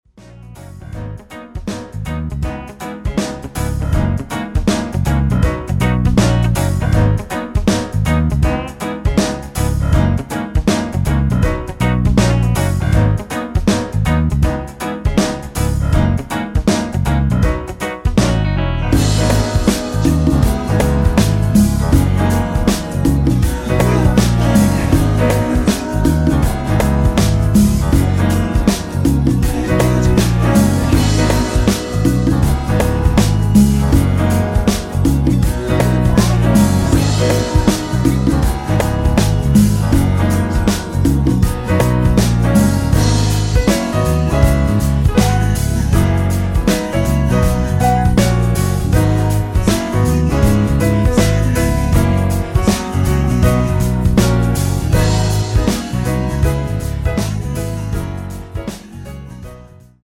코러스 포함된 MR 입니다.(미리듣기 참조)
Abm
◈ 곡명 옆 (-1)은 반음 내림, (+1)은 반음 올림 입니다.
앞부분30초, 뒷부분30초씩 편집해서 올려 드리고 있습니다.
중간에 음이 끈어지고 다시 나오는 이유는